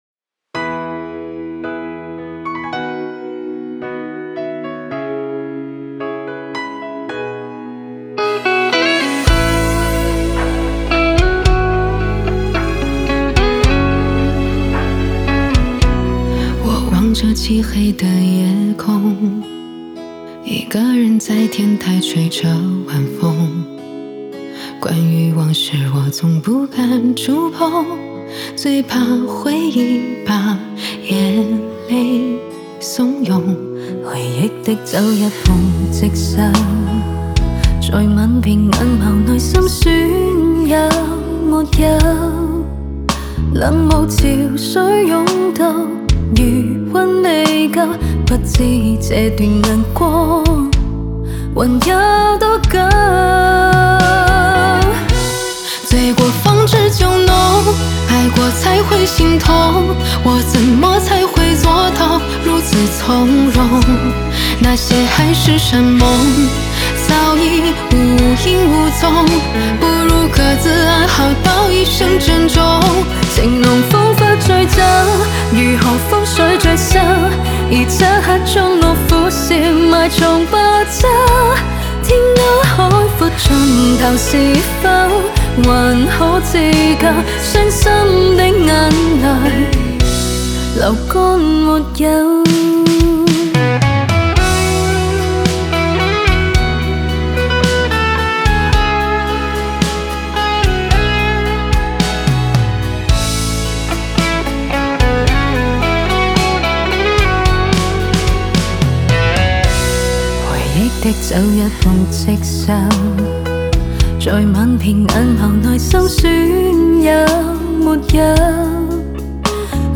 Ps：在线试听为压缩音质节选，体验无损音质请下载完整版 国语部分 我望着漆黑的夜空， 一个人在天台吹着晚风。